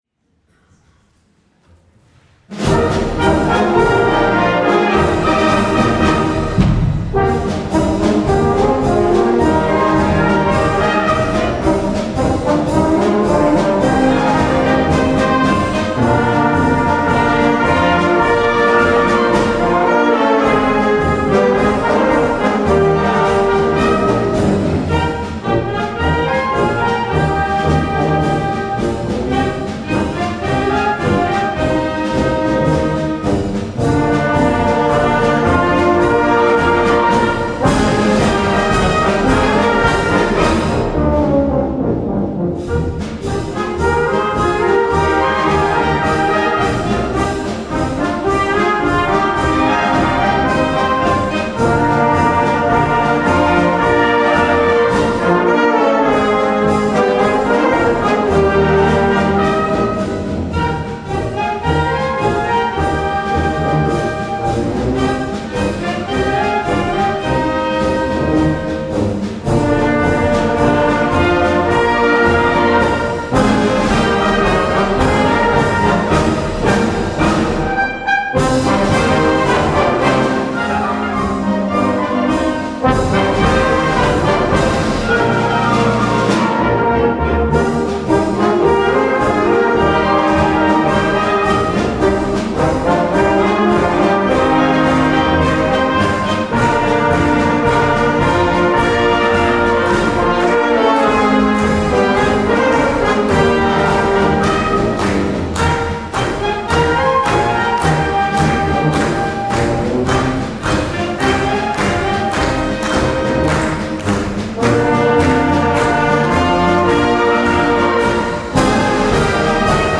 Jahreskonzert 2020
Musikgesellschaft St. Moritz
Zugaben: